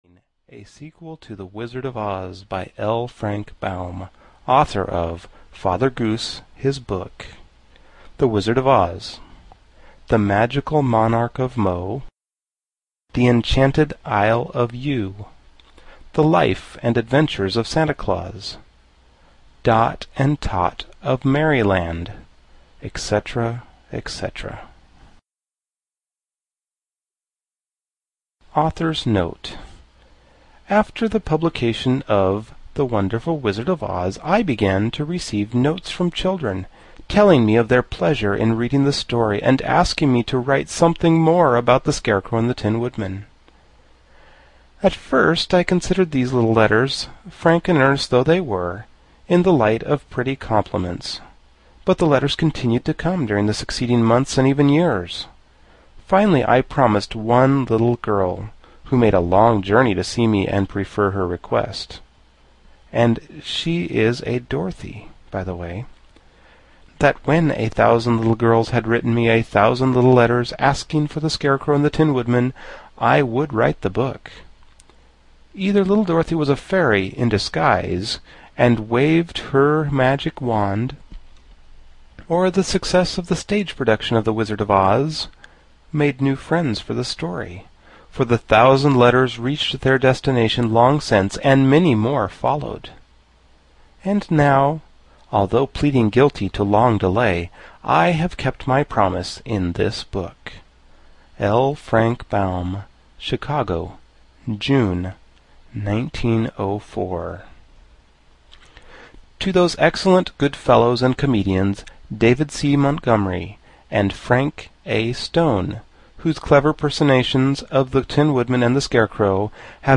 The Marvelous Land of Oz (EN) audiokniha
Ukázka z knihy